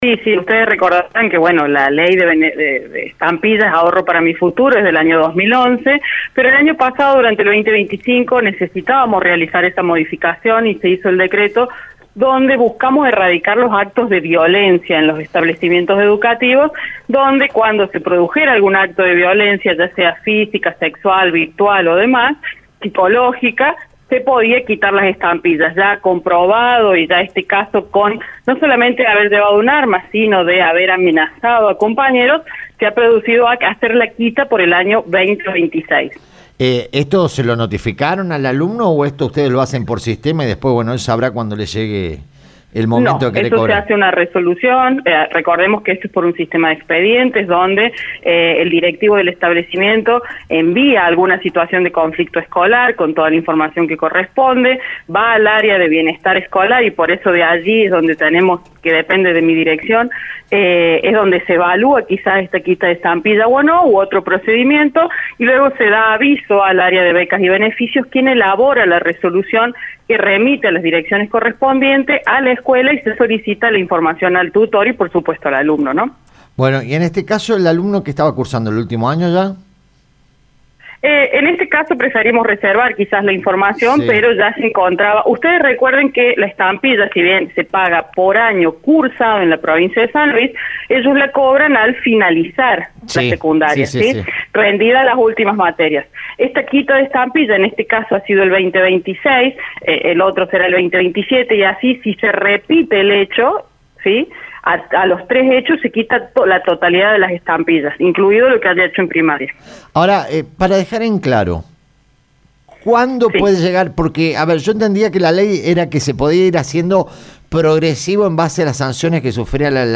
En diálogo con “El Show de la Tarde”, Celeste Aparicio, directora de Comunidad Educativa, confirmó la aplicación de la nueva reglamentación tras un grave episodio donde un estudiante portaba un arma blanca y amenazó a sus compañeros.